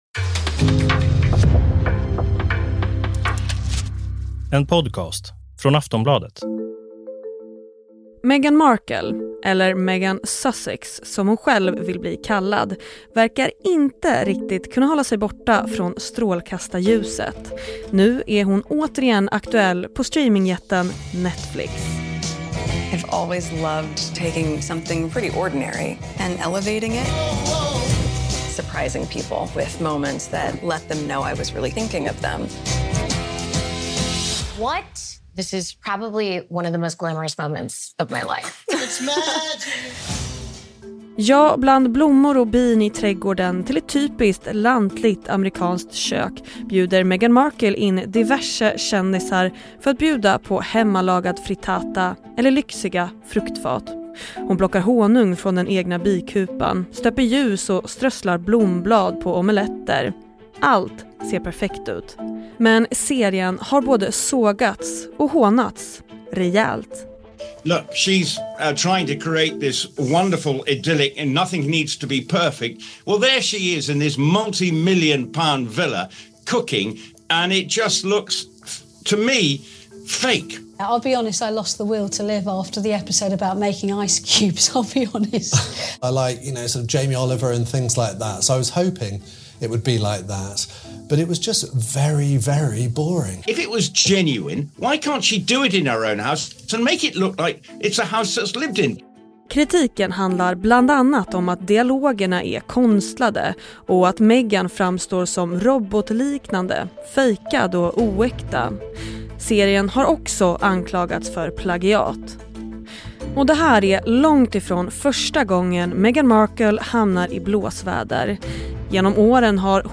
Klipp i avsnittet: Netflix, TALKTV, Daily Mail, Today, Sky News.